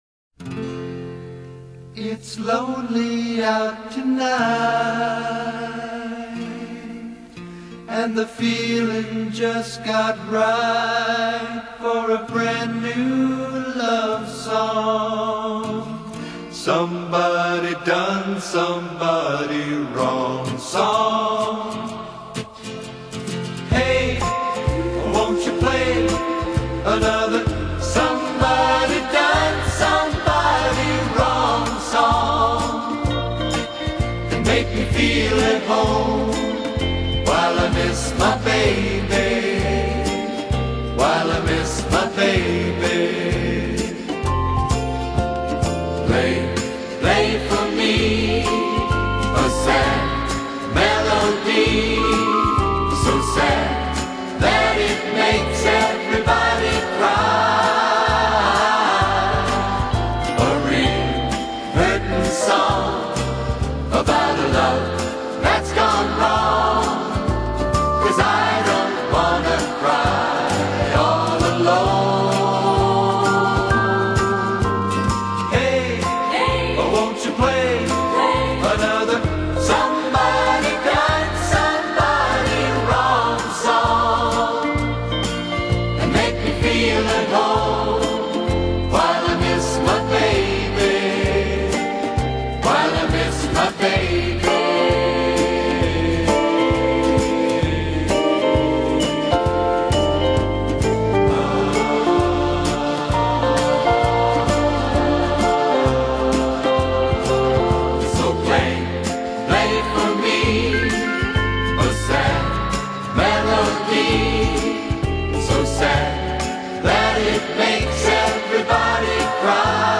他讓那些歌手們的歌唱取代樂團的許多工作，仿佛他們是樂器，而不是歌手。